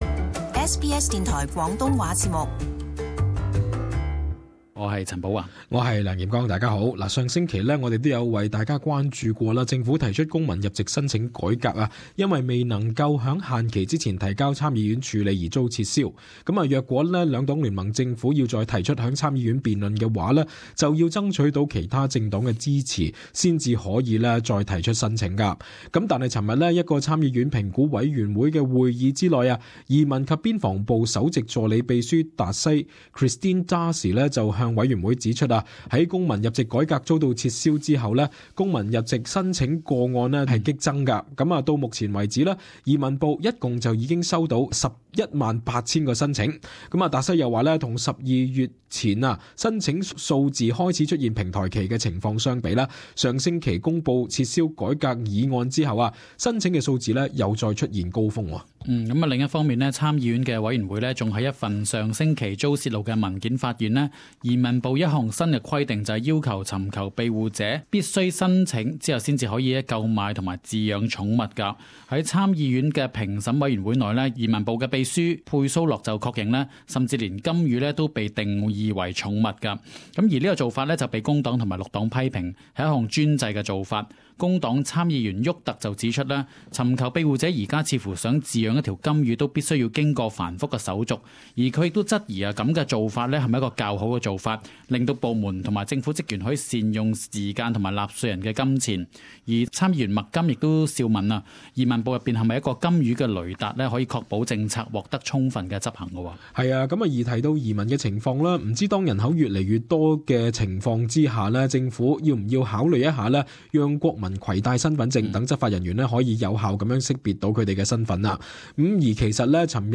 【時事報導】入籍法改革未果｜申請個案激增